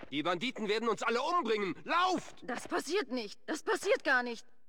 Fallout: Brotherhood of Steel: Audiodialoge
FOBOS-Dialog-Carbon-Bürger-002.ogg